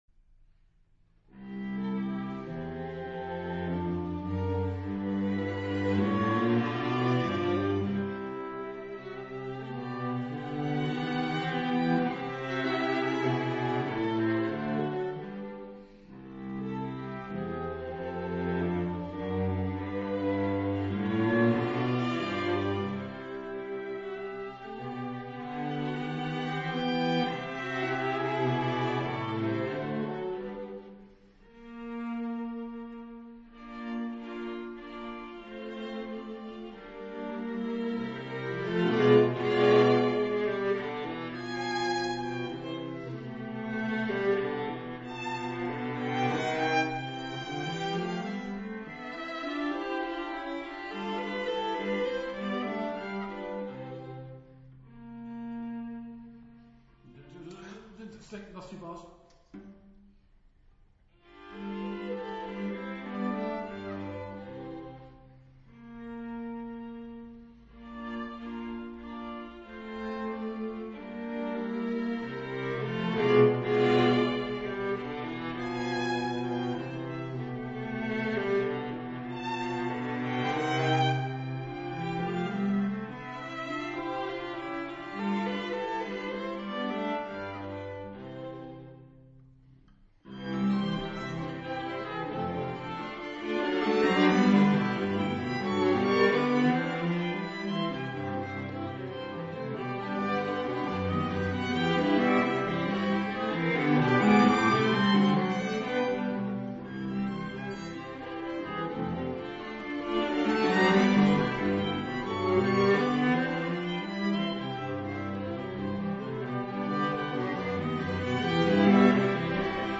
St John the Baptist, Aldbury. 2 2 15